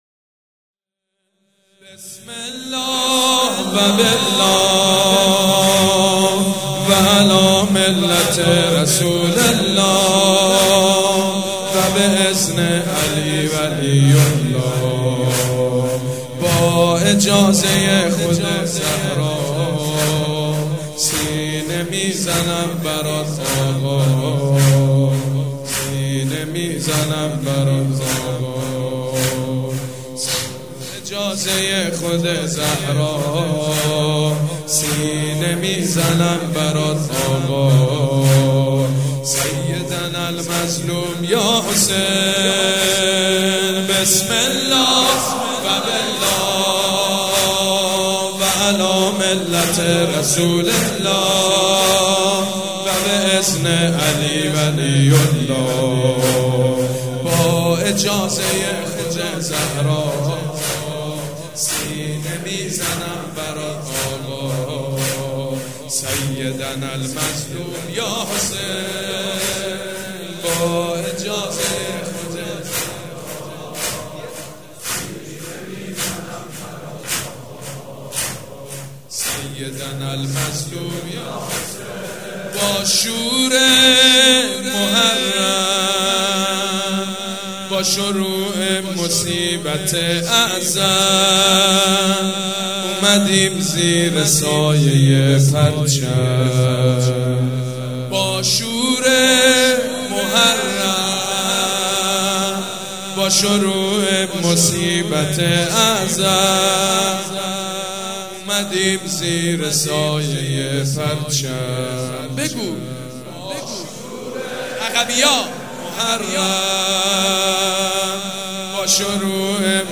شب سوم محرم الحرام‌ سه شنبه ۱3 مهرماه ۱۳۹۵ هيئت ريحانة الحسين(س)
سبک اثــر زمینه مداح حاج سید مجید بنی فاطمه